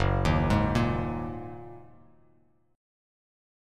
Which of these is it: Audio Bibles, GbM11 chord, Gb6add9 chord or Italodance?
Gb6add9 chord